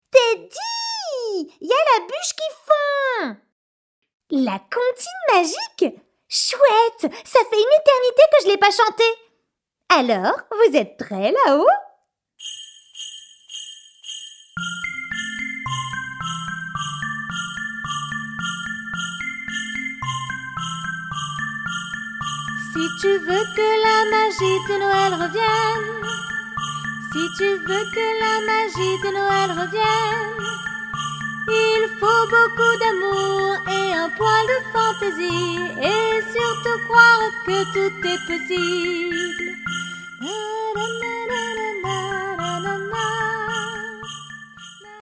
Démo voix - extraits fée clochette